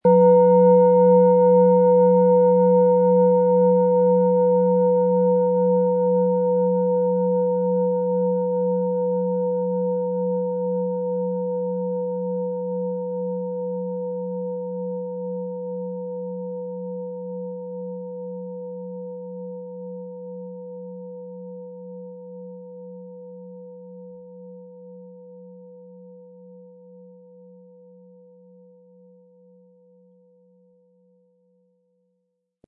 Planetenton 1
Planetenschale® Erkenne Deinen Lebensweg & Kreativ sein mit Jupiter, Ø 17 cm inkl. Klöppel
Unter dem Artikel-Bild finden Sie den Original-Klang dieser Schale im Audio-Player - Jetzt reinhören.
Aber uns würde der kraftvolle Klang und diese außerordentliche Klangschwingung der überlieferten Fertigung fehlen.
SchalenformBihar
MaterialBronze